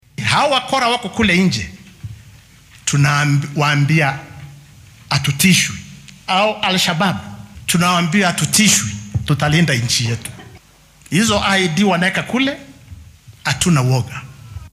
Taliyaha-guud-ee-booliiska-Koome.mp3